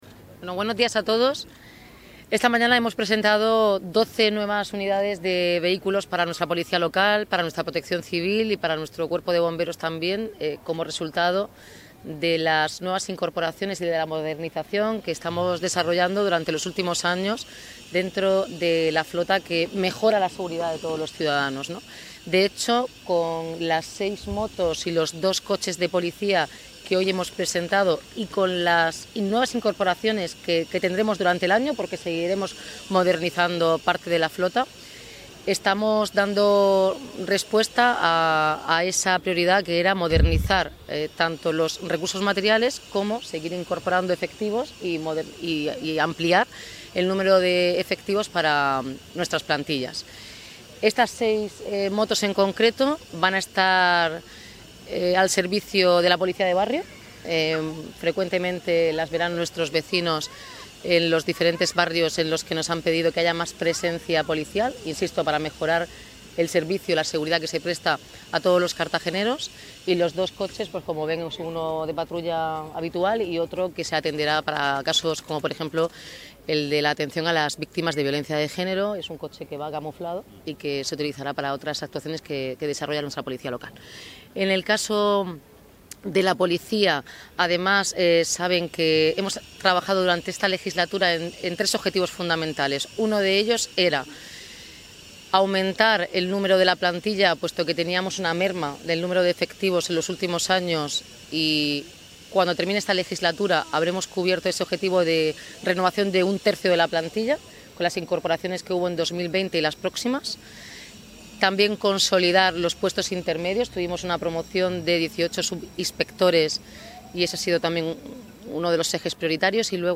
Enlace a Declaraciones de la alcaldesa Noelia Arroyo sobre la presentación de los nuevos medios para Policia Local y Bomberos